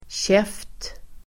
Uttal: [tjef:t]